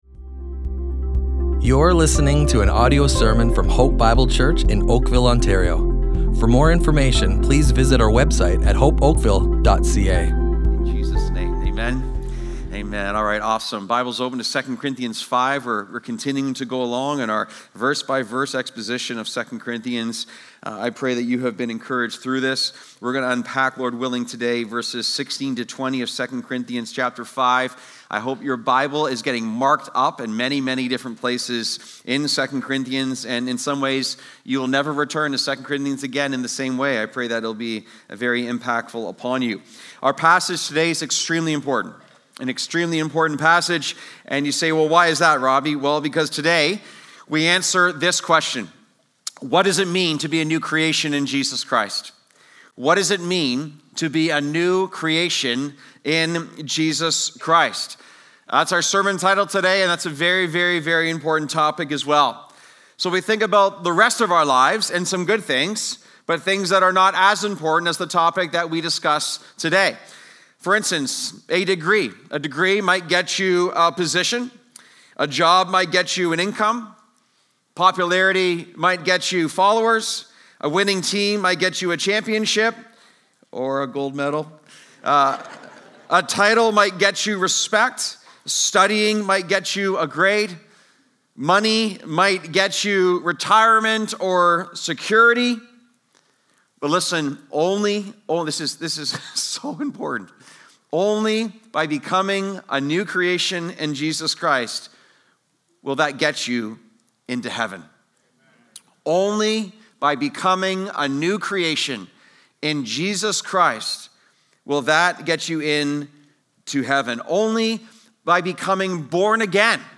Hope Bible Church Oakville Audio Sermons Strength in Weakness // What Does it Mean to be a New Creation?